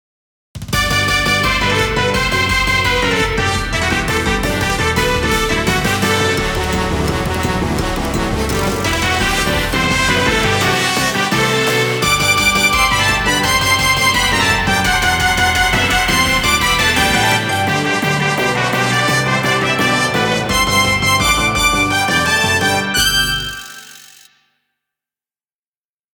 ダークが色濃いシリーズです。